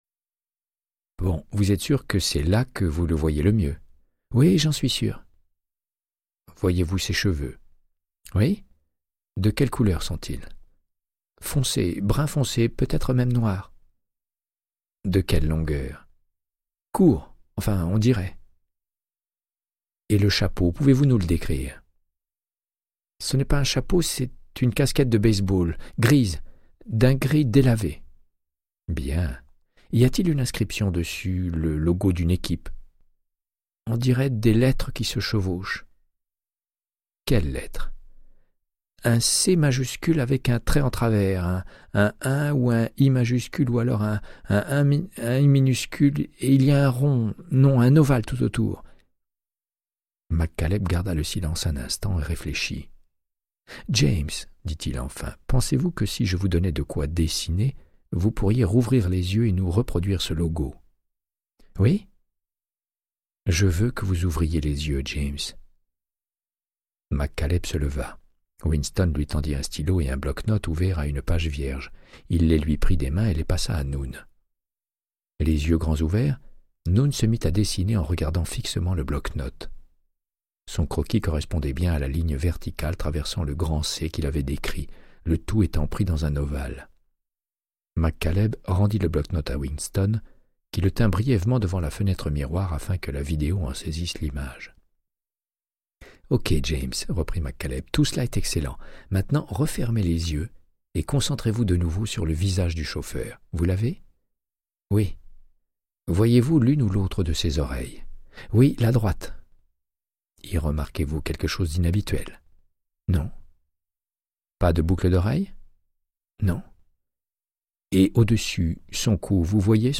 Audiobook = Créance de sang, de Michael Connellly - 70